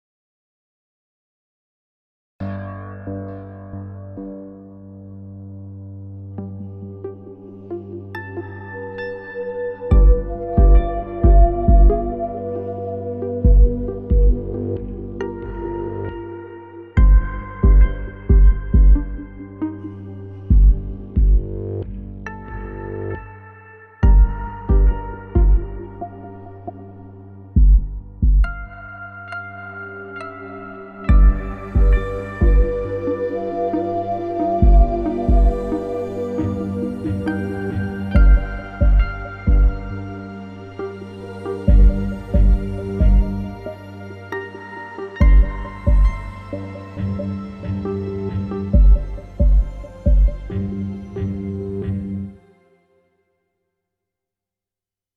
听着住悠扬的背景音乐